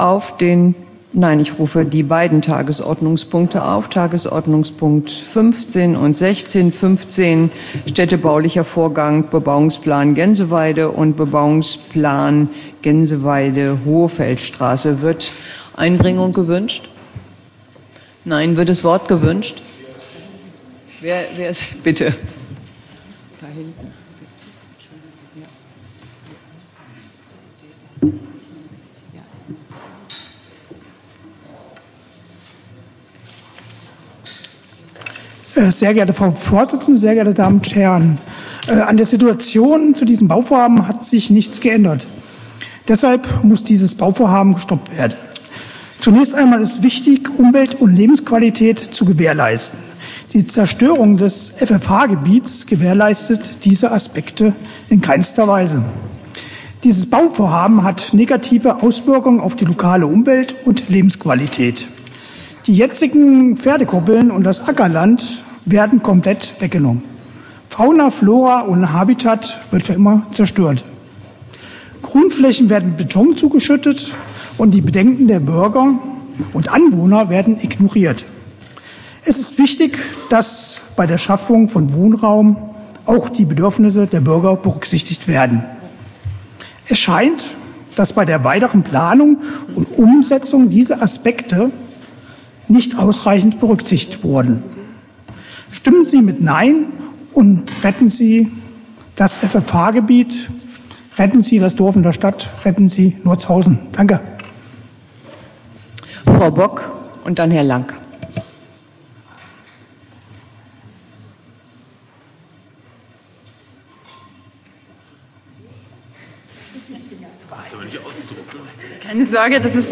Tonaufzeichnung Tagesordnungspunkte 15 und 16 (exportiert: 05.12.2023)